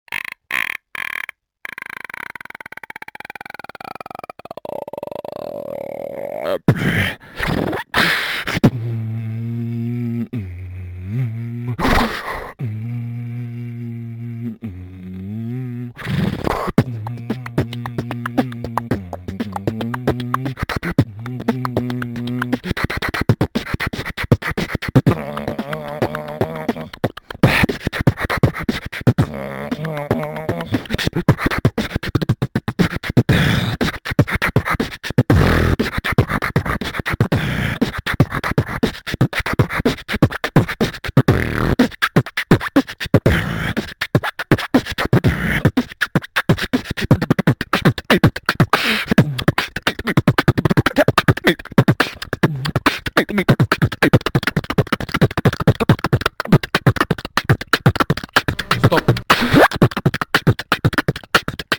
21.05.2011 состоялся баттл
на нашем русском сервере Вентры